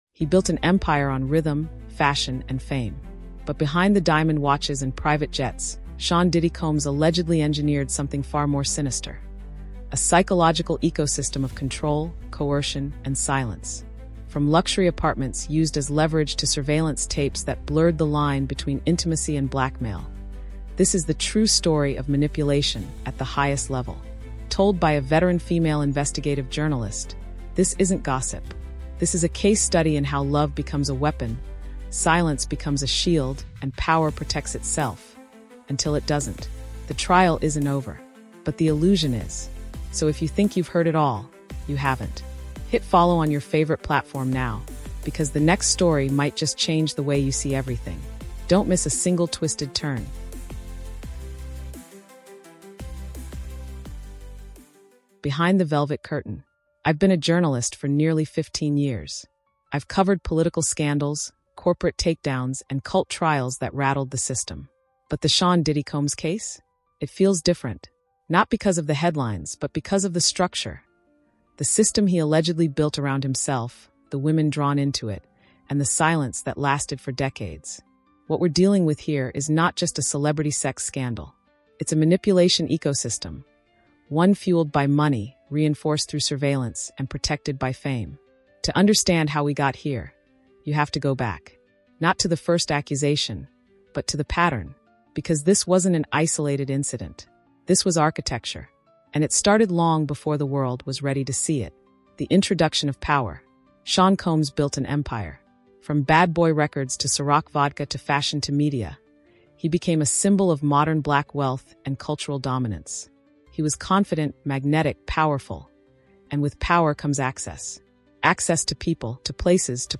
MANIPULATION: The Power, The Parties, and the Predator — Inside the Diddy Case is an eight-part true crime audiobook podcast that dissects the federal case against Sean “Diddy” Combs through the eyes of a seasoned female investigative journalist. Blending psychological realism, fact-based storytelling, and thriller pacing, this series exposes the mechanisms of emotional manipulation, sex trafficking, media silencing, and systemic abuse behind one of the music industry’s most protected figures.